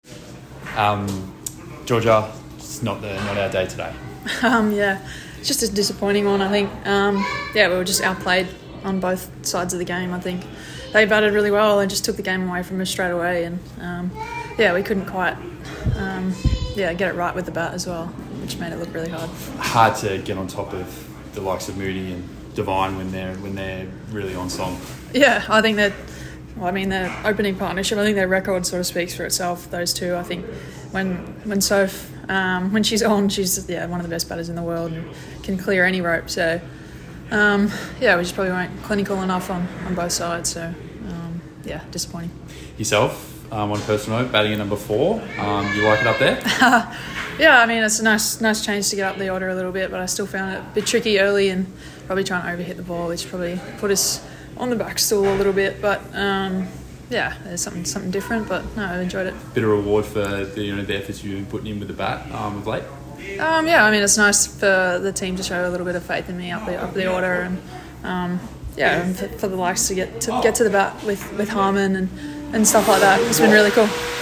Words from Georgia Wareham (57* batting at No. 4) following today’s loss to the Scorchers at CitiPower Centre.